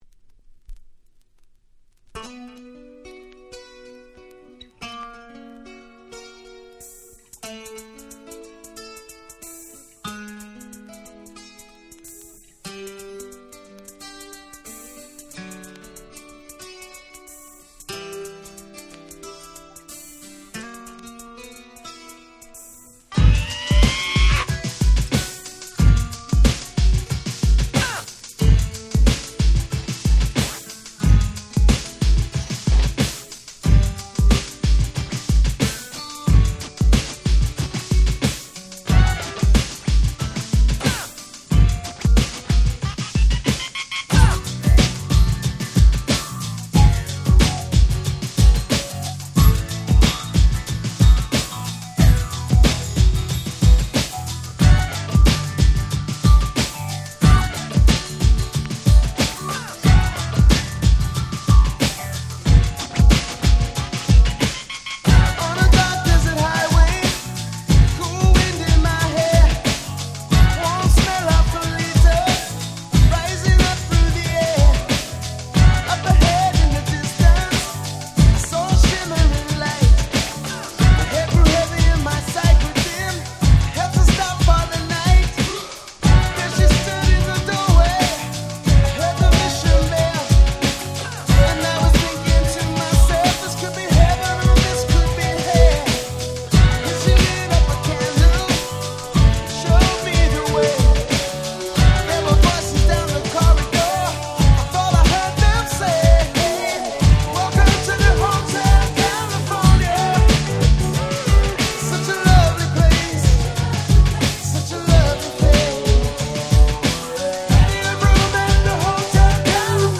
90' Nice Cover Ground Beat !!
まさに教科書通りなグラウンドビートカバーで◎！